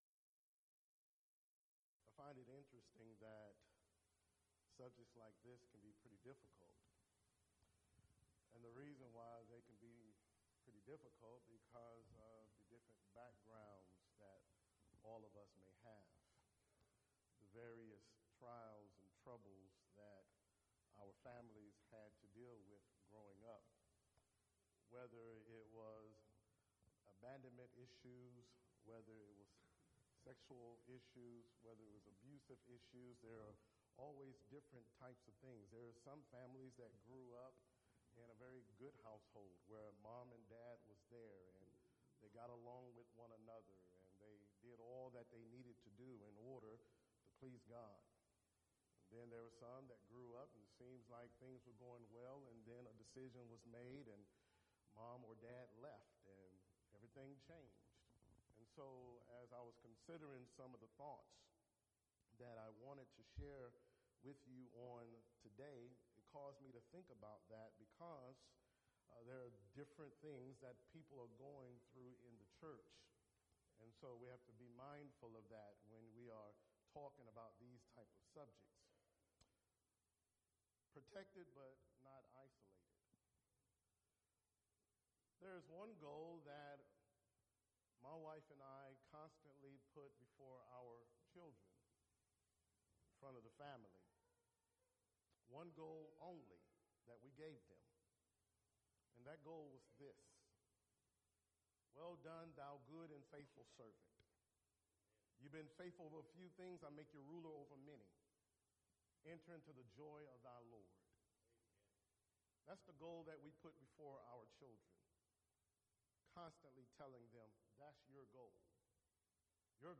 Event: 3rd Annual Southwest Spritual Growth Workshop Theme/Title: Arise and Grow as a Family
lecture